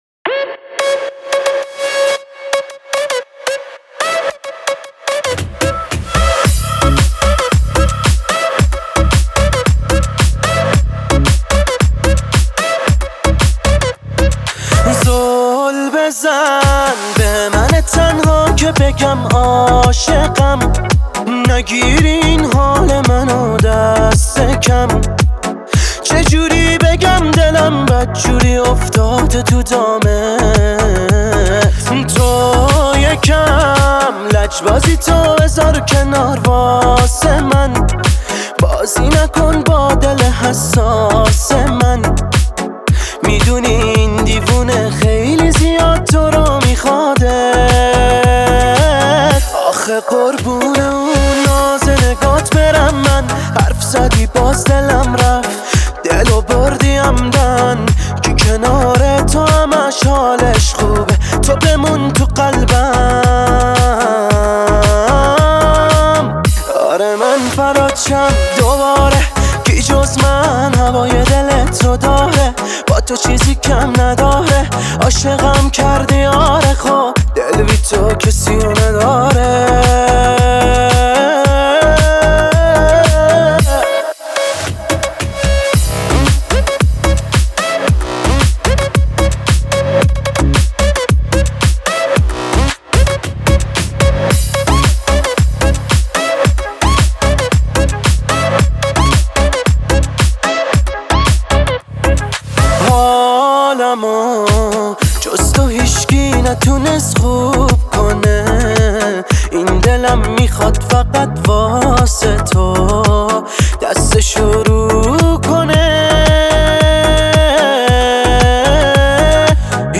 پر انرژی